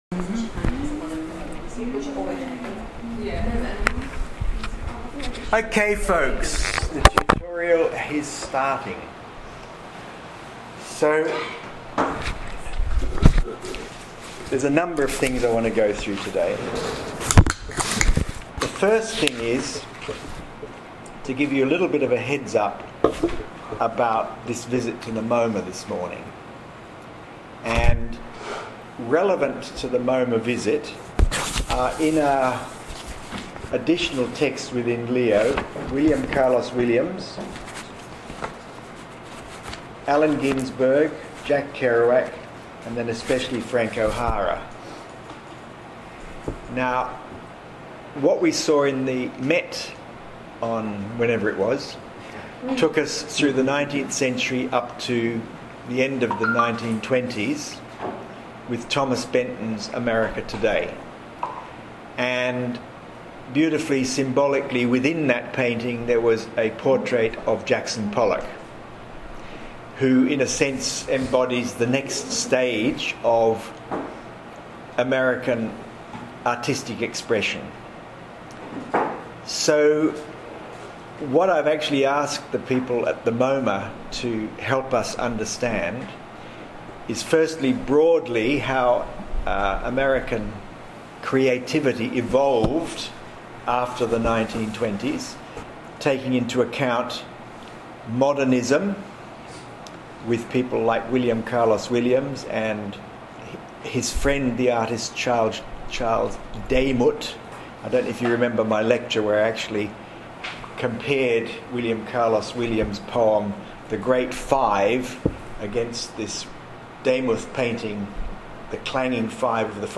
Here is the full audio lecture/ floor talk and attached below are some of the key images of paintings, kinetic sculptures and architectural details that deepened our understanding of what was happening in the contemporary literary scene.
When listening, be patient as we spent some time wandering from one painting to the next. Here is the audio of today’s lecture: